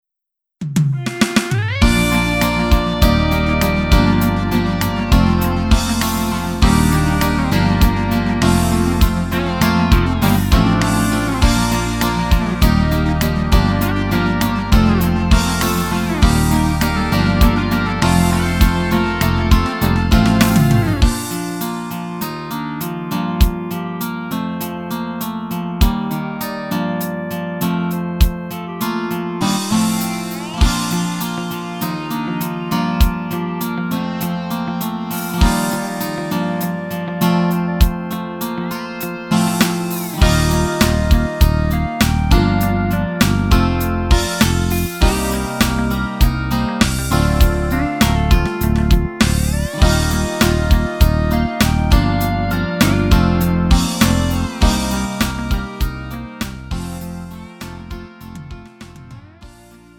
음정 원키 3:52
장르 가요 구분 Lite MR